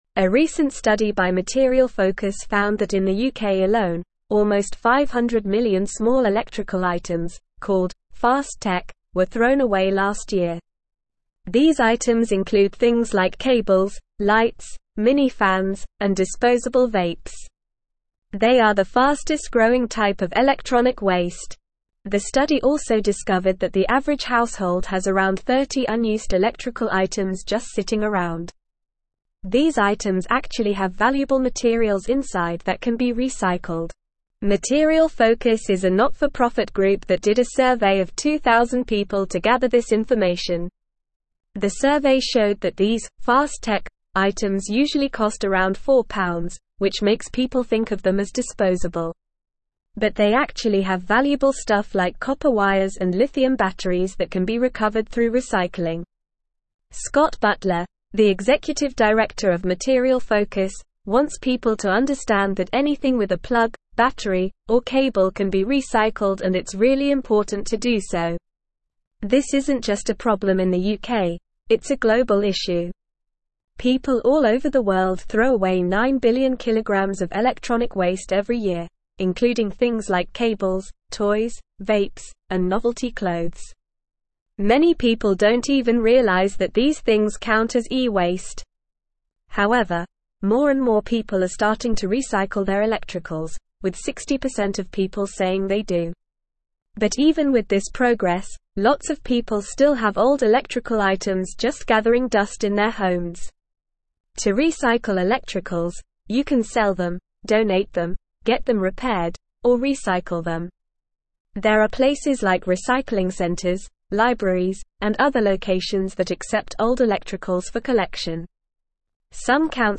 Normal
English-Newsroom-Upper-Intermediate-NORMAL-Reading-UK-Urged-to-Recycle-Fast-Tech-E-Waste.mp3